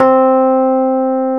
RHODES2H C4.wav